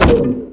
drop.mp3